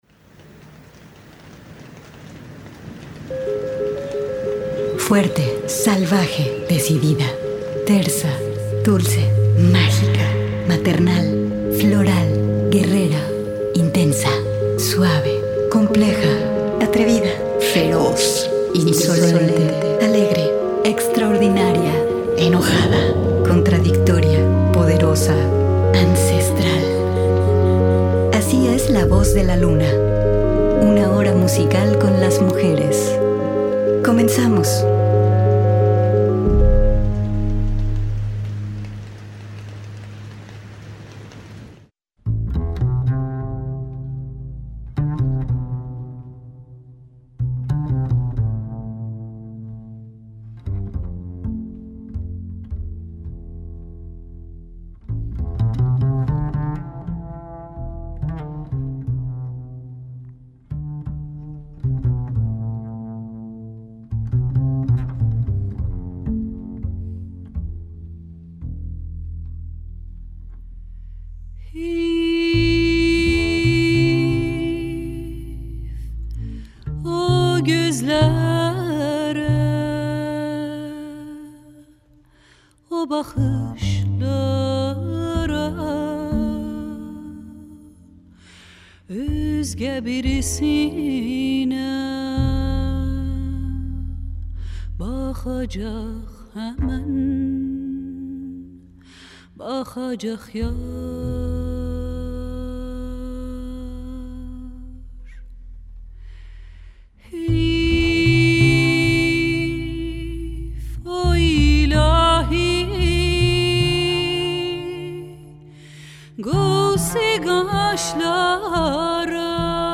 Hoy tenemos música especial con voces femeninas que están marcando su presente desde distintas partes del mundo.